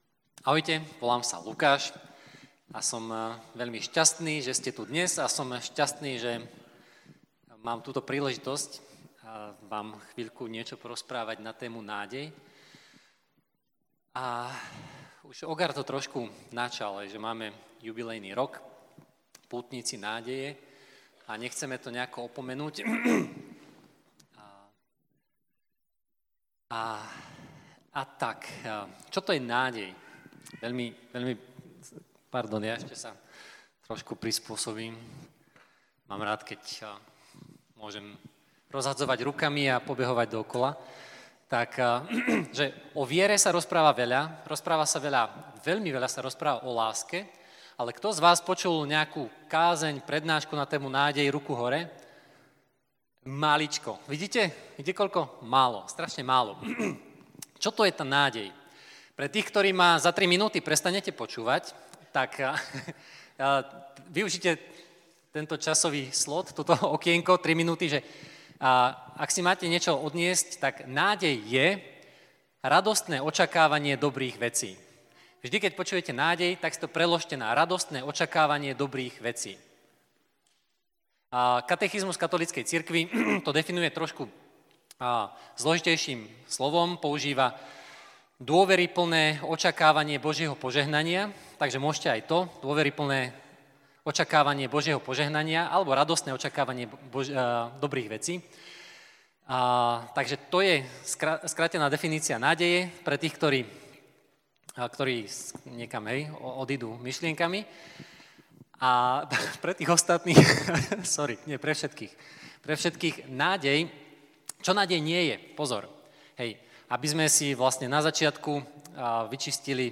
Motto jubilejného roka 2025 je “Pútnici nádeje” a tak aj na januárovom Open Ebene odznelo slovo práve na tému “Nádej”.